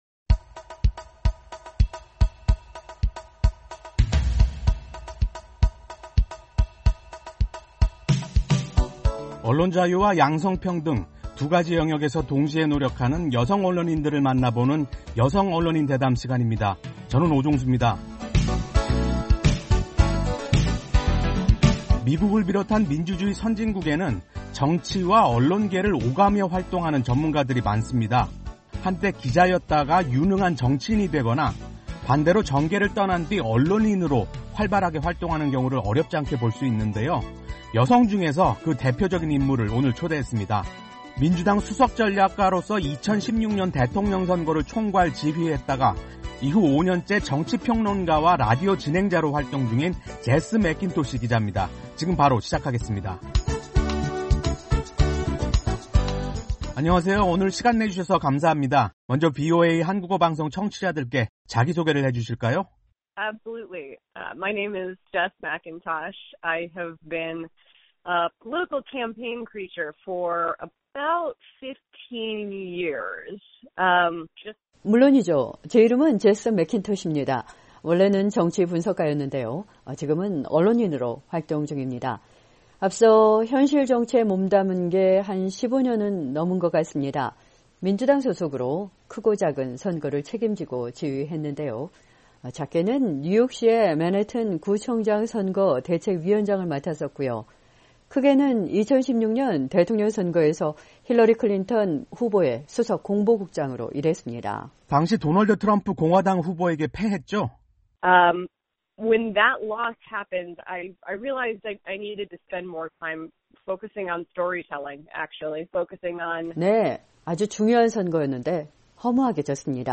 [여성 언론인 대담] "여성 분장 시간 줄일 수 없을까요?"